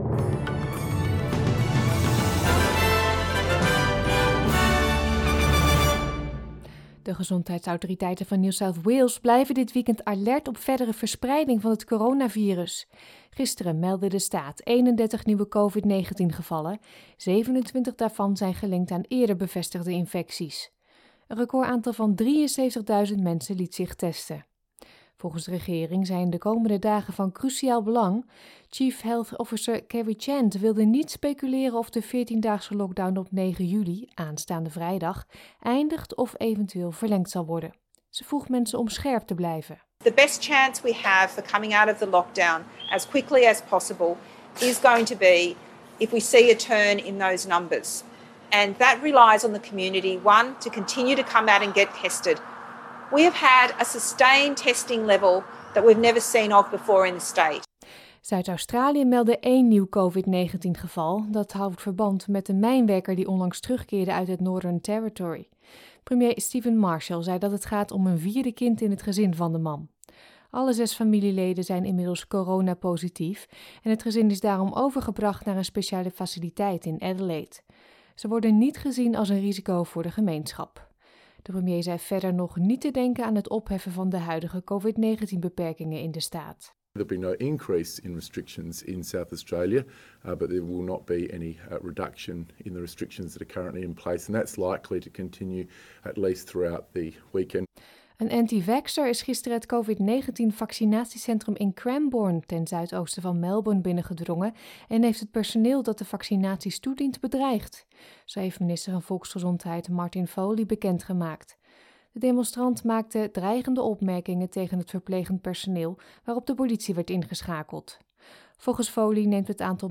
Nederlands/Australisch SBS Dutch nieuwsbulletin van zaterdag 3 juli 2021